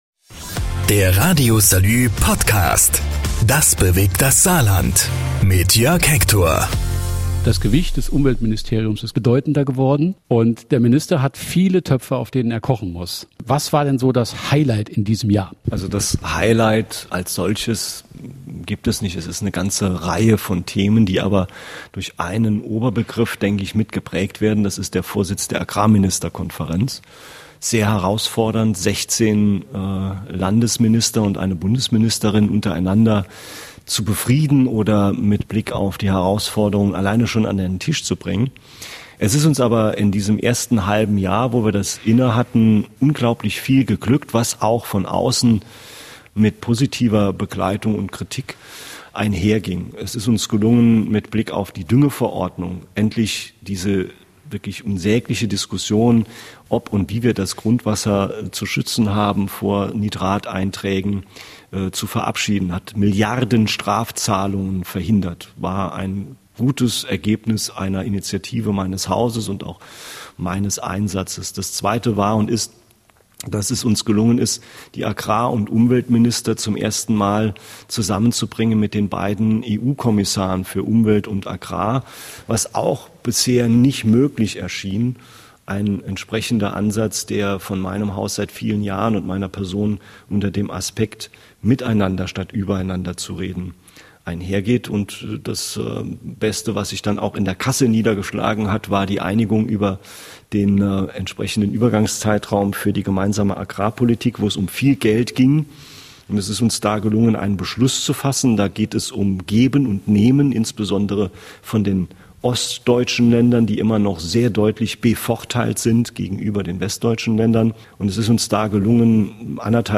Folge 11: Klimaschutz - Gespräch mit Umweltminister Reinhold Jost ~ RADIO SALÜ - Das bewegt das Saarland Podcast